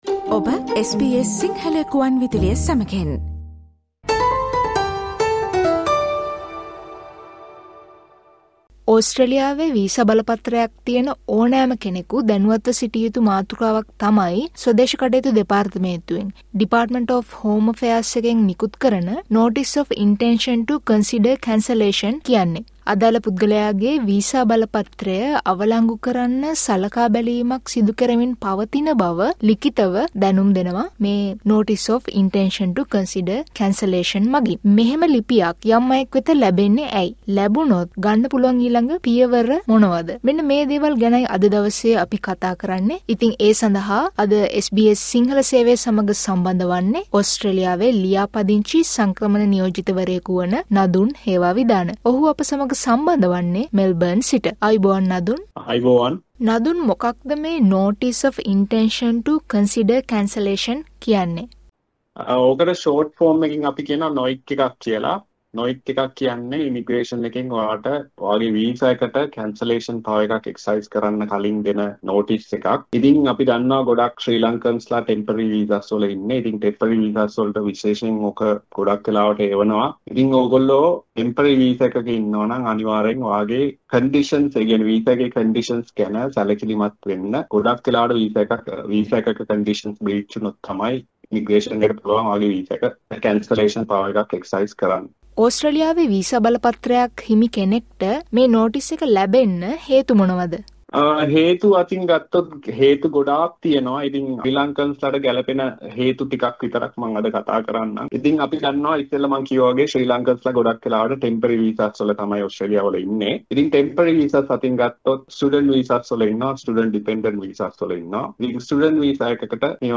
ඕස්ට්‍රේලියාවේ වීසා බලපත්‍රයක් ඇති ඕනෑම කෙනෙකු දැනුවත්ව සිටිය යුතු Notice of intention to consider cancellation යනු පුද්ගලයෙකුගේ වීසා බලපත්‍රය අවලංගු කිරීමට සලකා බැලීමක් සිදු කෙරෙන බව ලිඛිතව දැනුම්දීමයි. මෙවැනි ලිපියක් ලැබීමට හේතු සහ ගත යුතු පියවර පිළිබඳ SBS සිංහල සේවය කළ සාකච්ඡාවට සවන් දෙන්න.